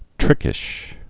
(trĭkĭsh)